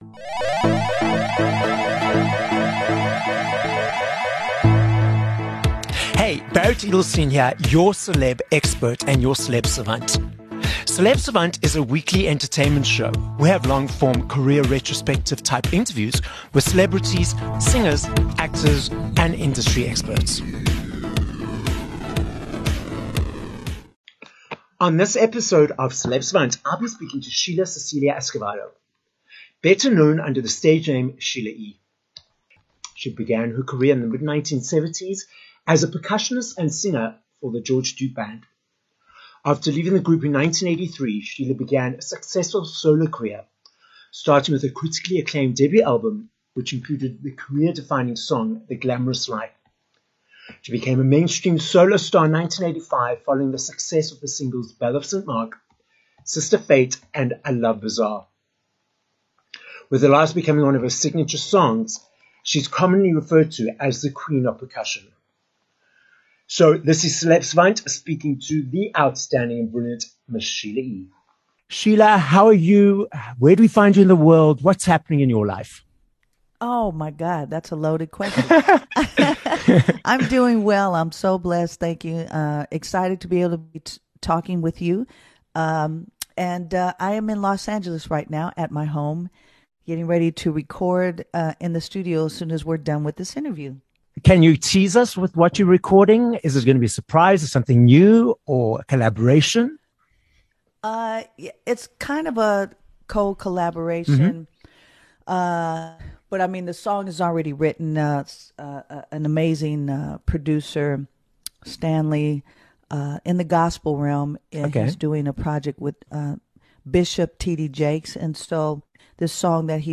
20 Sep Interview with Sheila E